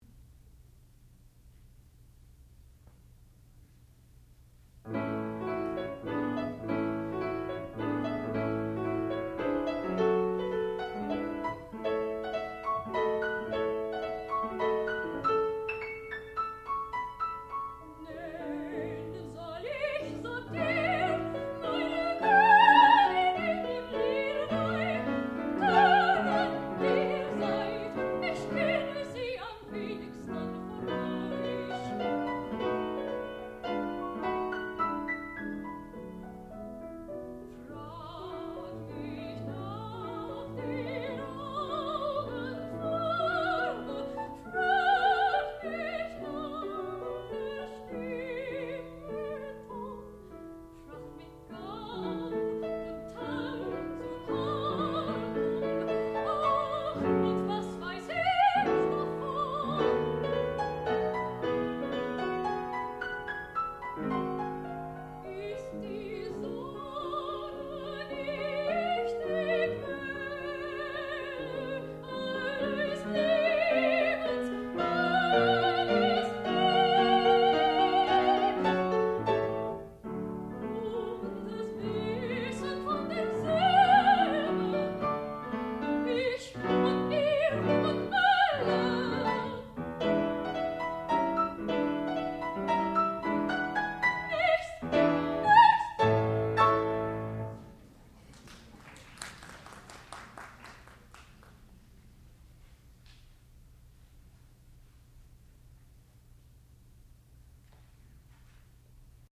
sound recording-musical
classical music
soprano
piano and harpsichord